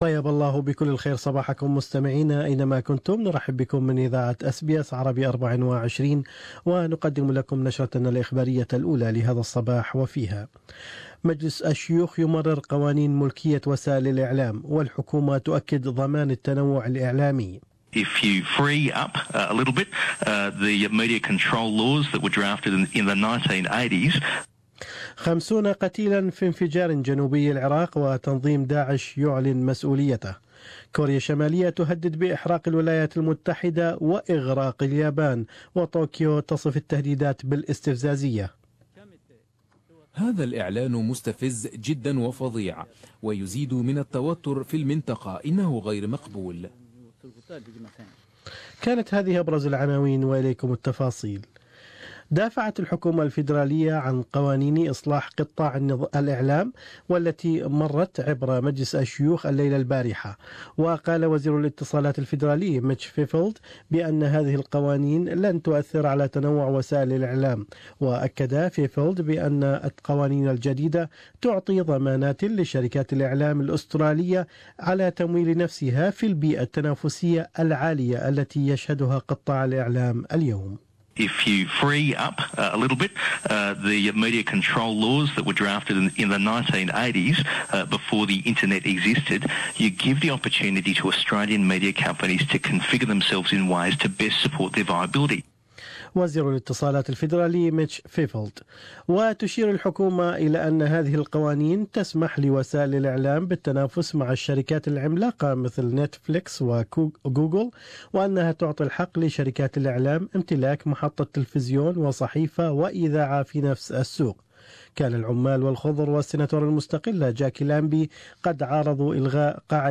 News Bulletin: Govt. says media reforms will not affect media diversity